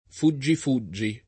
vai all'elenco alfabetico delle voci ingrandisci il carattere 100% rimpicciolisci il carattere stampa invia tramite posta elettronica codividi su Facebook fuggi fuggi [ f 2JJ i f 2JJ i ] (raro fuggifuggi [id.]) s. m.